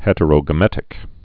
(hĕtə-rō-gə-mĕtĭk)